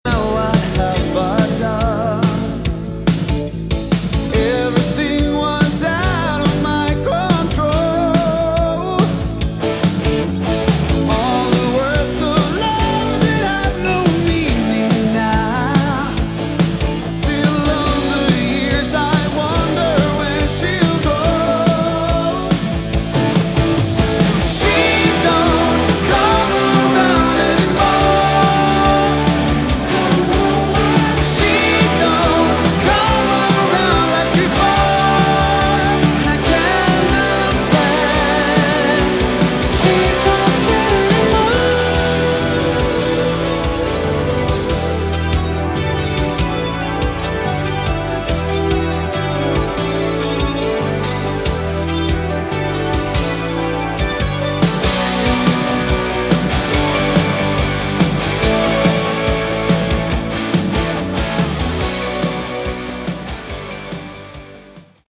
Category: AOR / Melodic Rock
lead, backing vocals
lead guitar
keyboards, piano
bass guitar